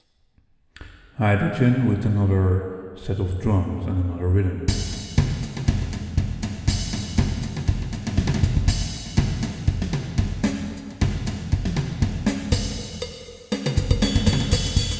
Here are example with a microphone speaking over the impule reverb, and starting a piece of the drum demos, also sounding over the reverb (which is a hard test):